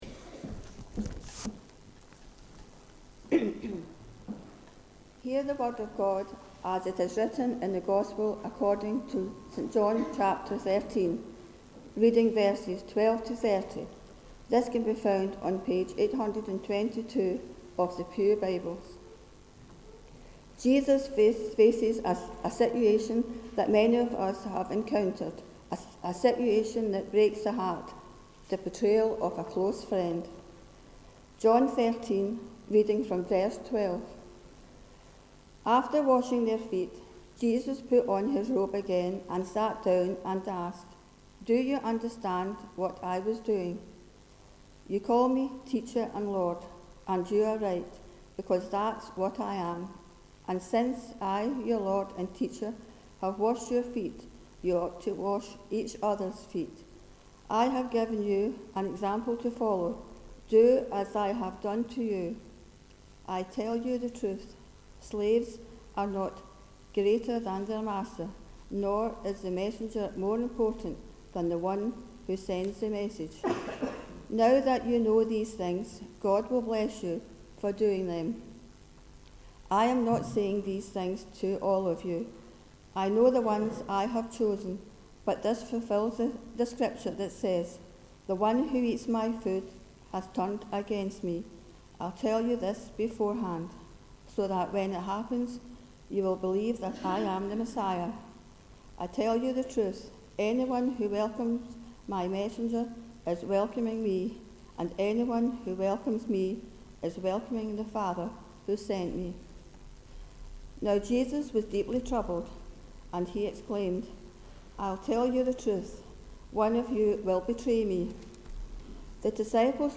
The reading prior to the Sermon is John 13: 12-30.